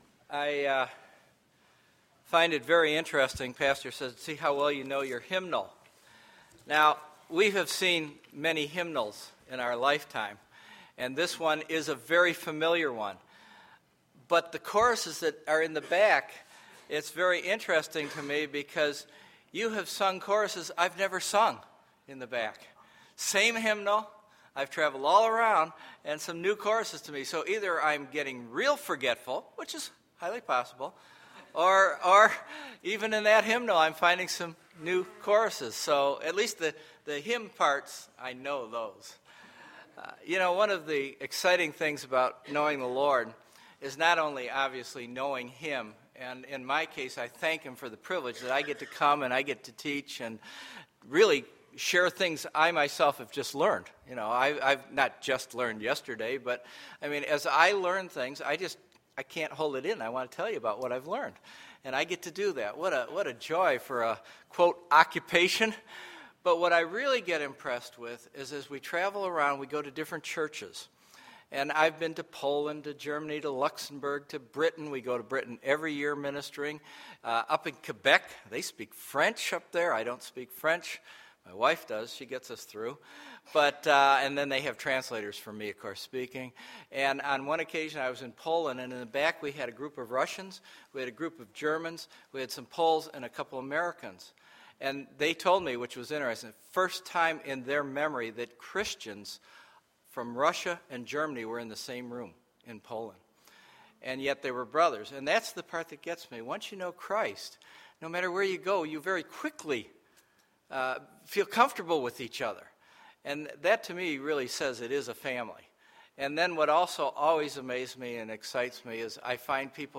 Sunday, October 23, 2011 – Morning Message